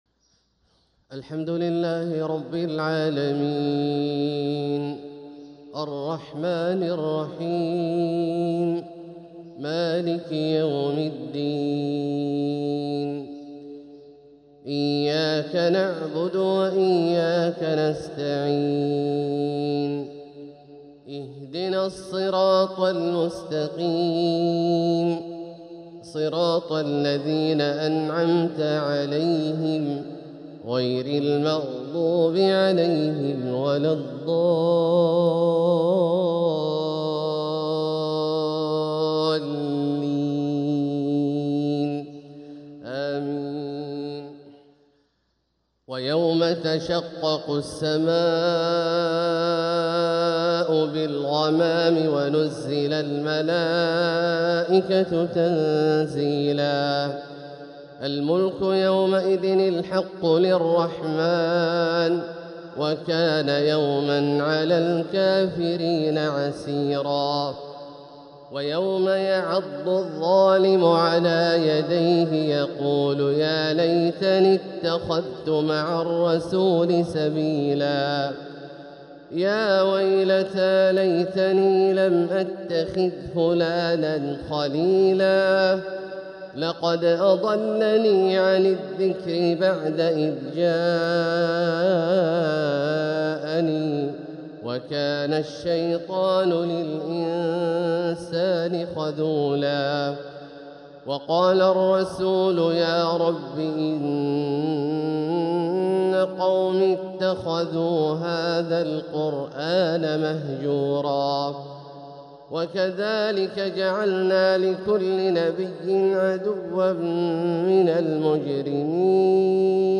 تلاوة من سورة الفرقان 25-56 | فجر الثلاثاء 4-6-1447هـ > ١٤٤٧هـ > الفروض - تلاوات عبدالله الجهني